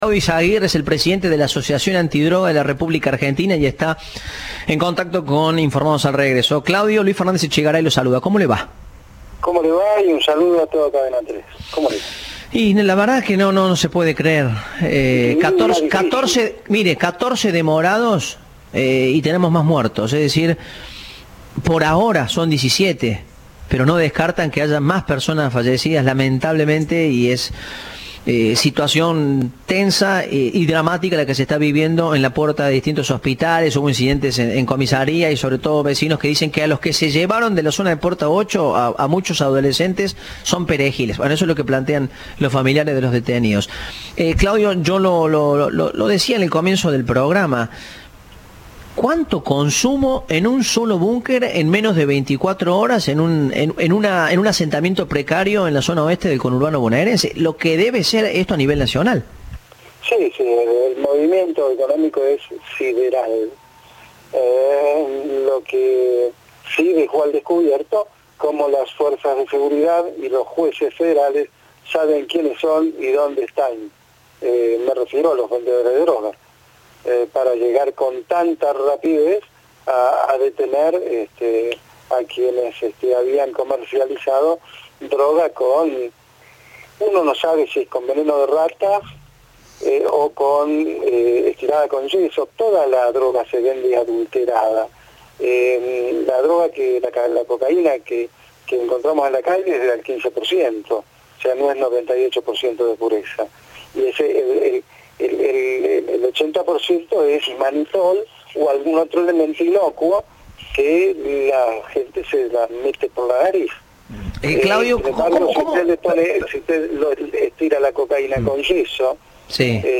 Entrevista de "Informados, al regreso"